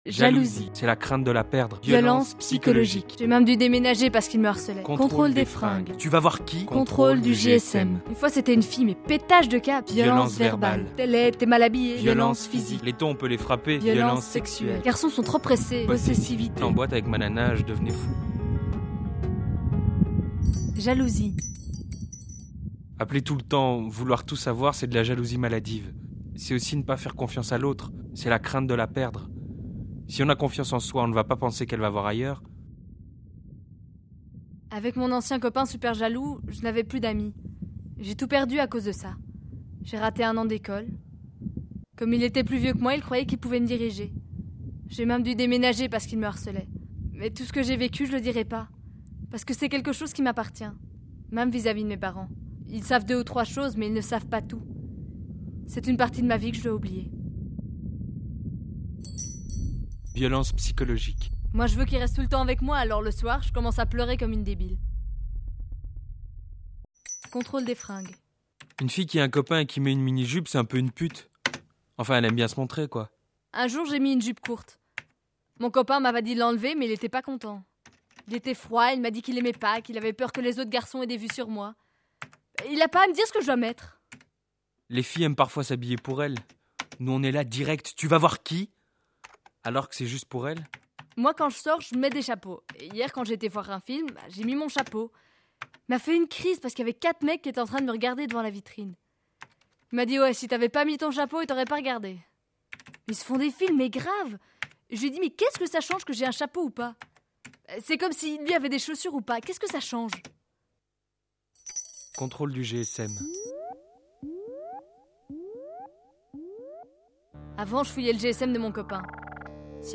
Témoignage audio
Ces témoignages, issus d’une enquête menée par la Fédération Wallonie-Bruxelles, ont été enregistrés dans le cadre de l'émission radiophonique Quand les jeunes s'en mêlent, diffusée sur la Première-RTBF, le samedi 16 février 2008.